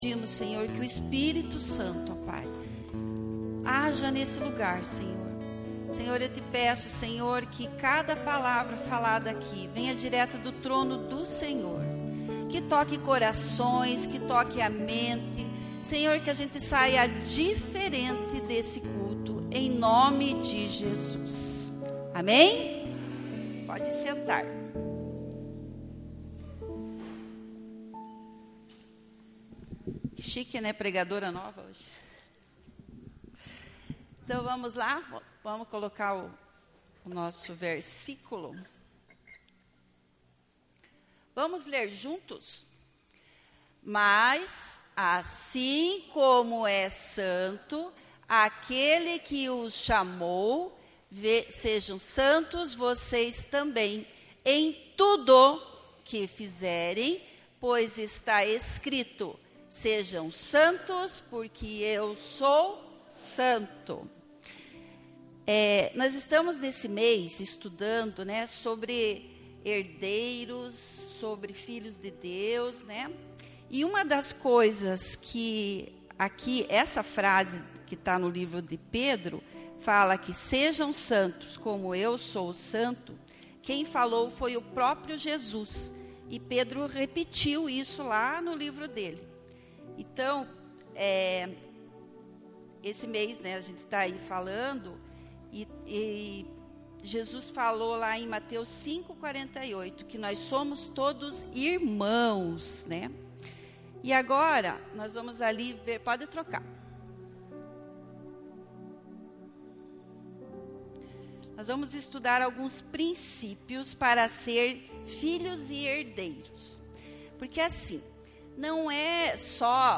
LEITURA DA BÍBLIA